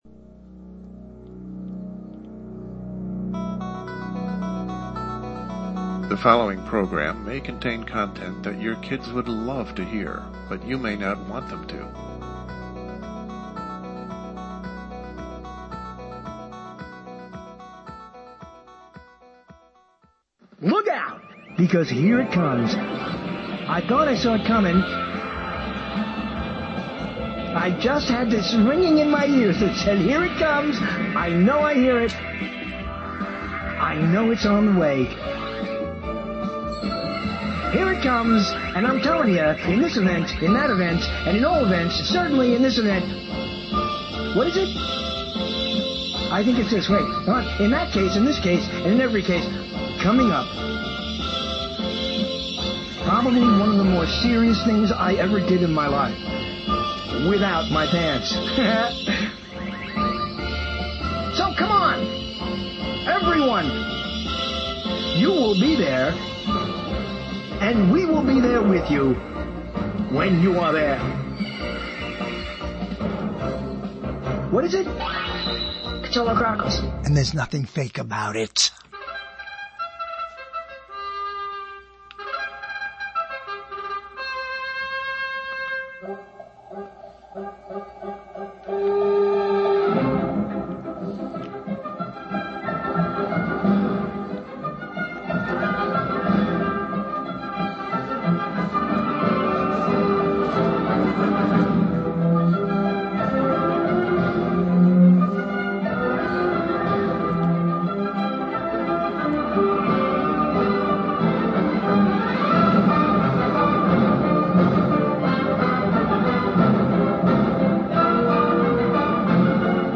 Most people have believed anything they read but now there is a slew more to read than ever before, the web is lousy with fake news. On our Dec. 1 show, LIVE at 9:00 p.m. Eastern time we offer a guide to misled information, to the phony-baloney reports that dismiss the function of journalism by relying on the function of fiction.